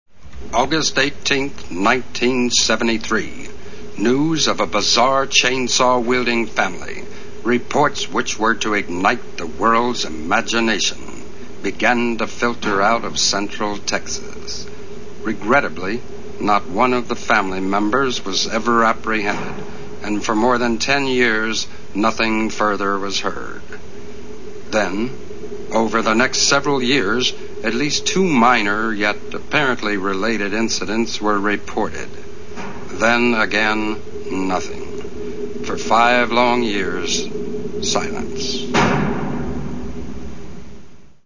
Opening Narration [ 41 seconds ]